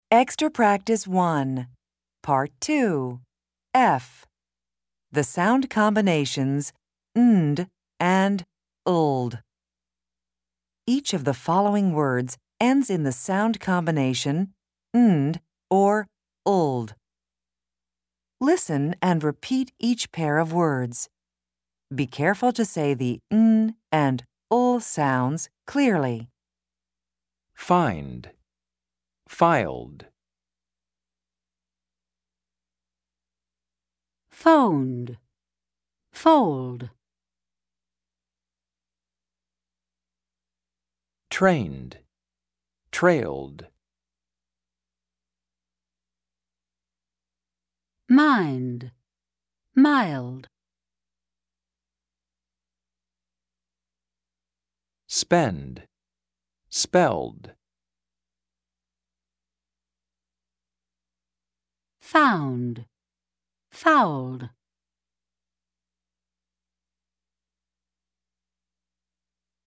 Pronunciation and Listening Comprehension in North American English
American English